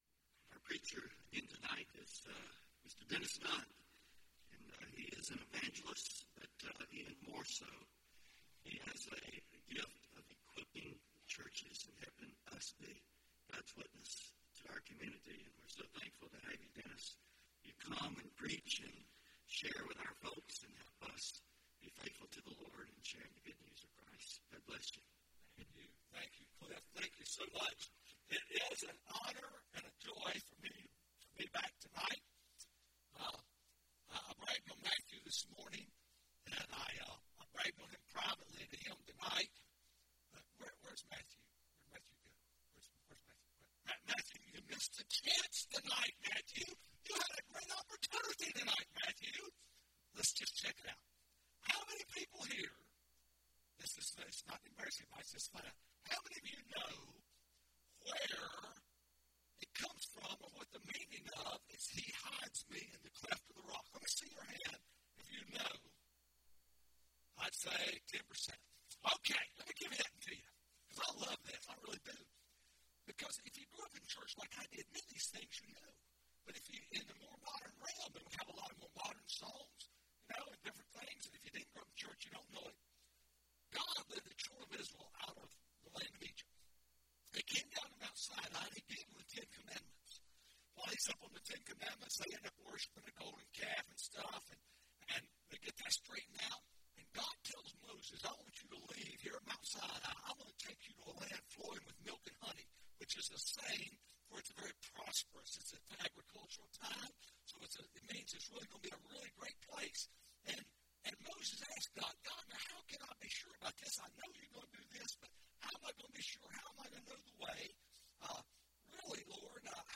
Guest Speaker
Sermon